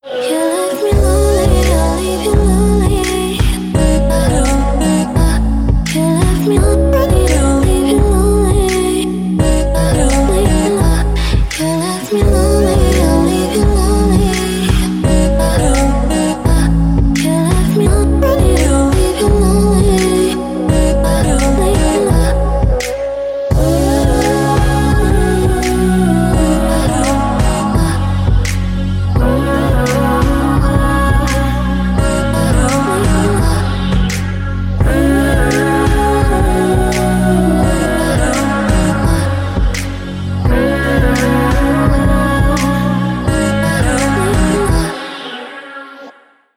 • Качество: 320, Stereo
красивые
dance
спокойные
Chill Trap